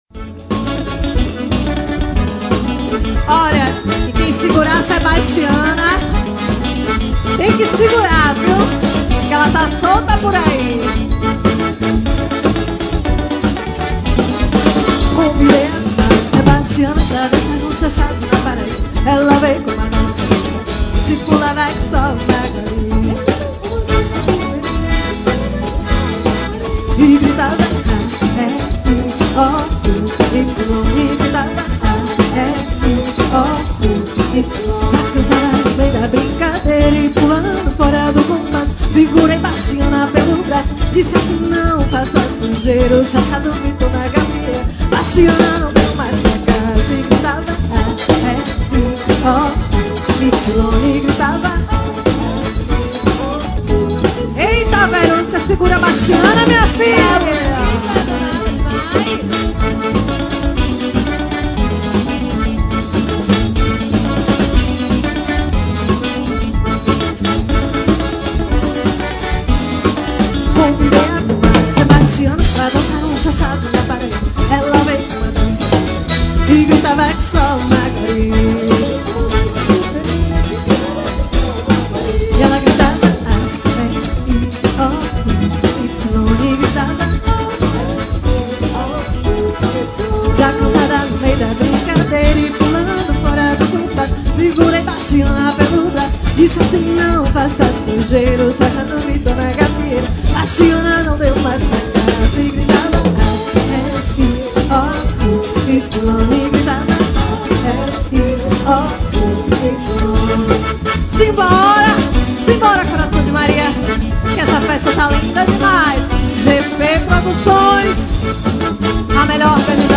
Show ao vivo